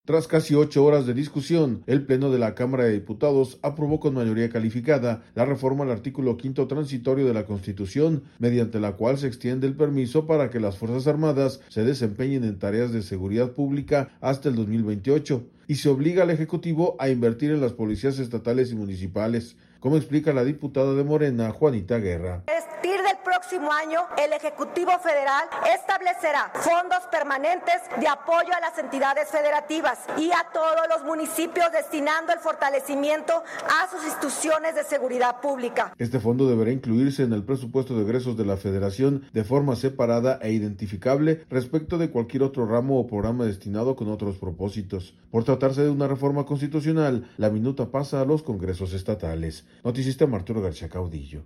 Tras casi ocho horas de discusión, el Pleno de la Cámara de Diputados aprobó con mayoría calificada, la reforma al artículo Quinto Transitorio de la Constitución, mediante la cual se extiende el permiso para que las Fuerzas Armadas se desempeñen en tareas de seguridad pública hasta el 2028, y se obliga al Ejecutivo a invertir en las policías estatales y municipales, como explica la diputada de Morena, Juanita Guerra.